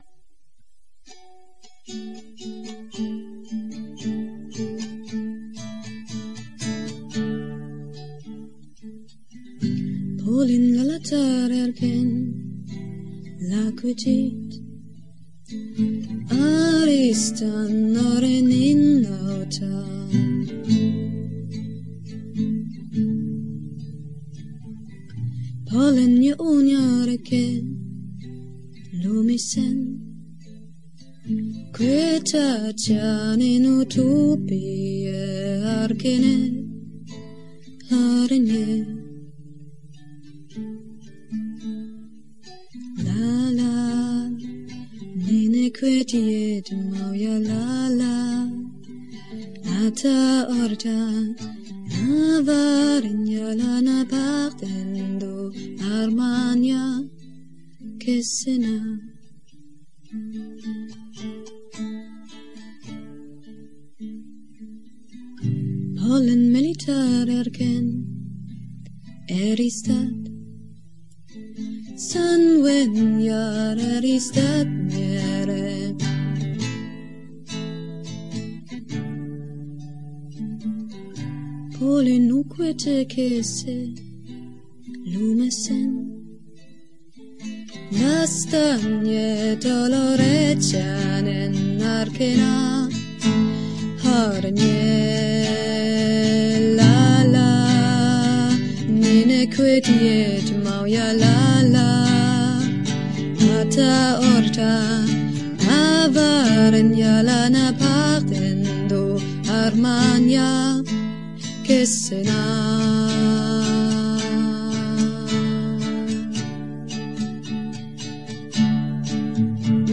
Edhellen 'lirnen - Sung Elvish